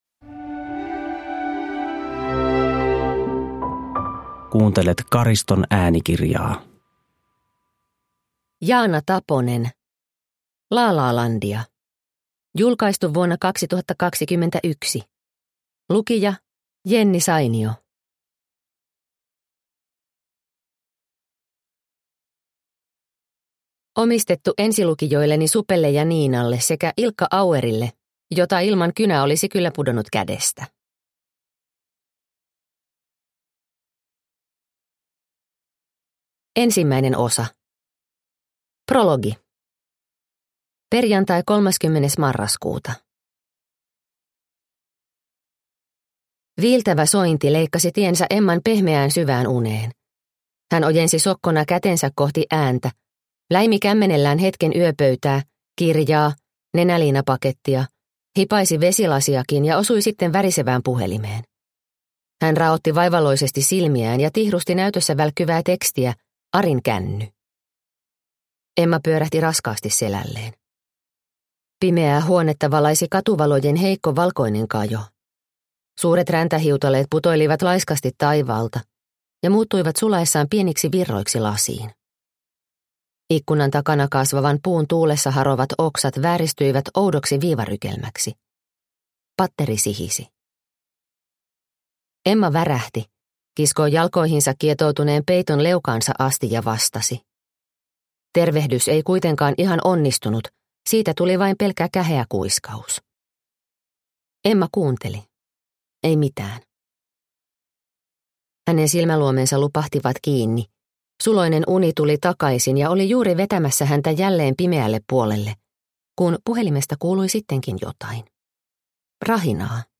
Lalalandia – Ljudbok – Laddas ner